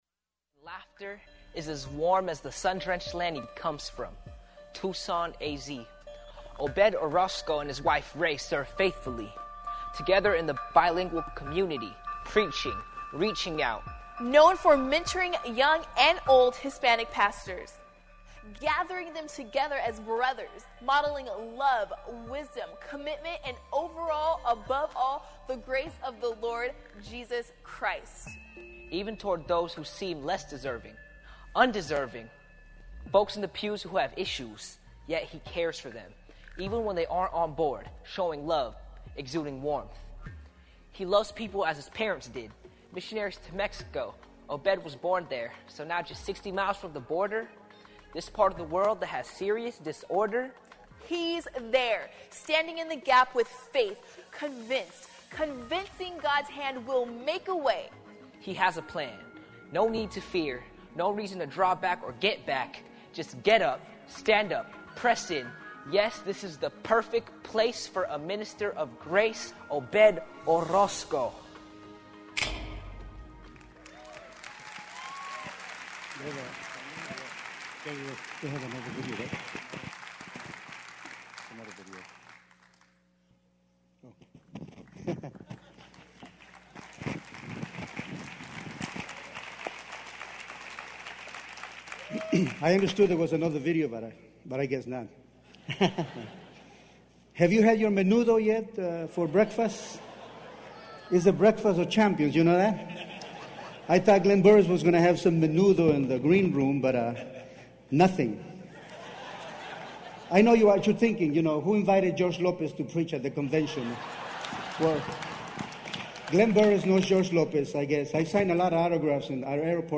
Workshop
Recorded live at Connection 2012 in Phoenix.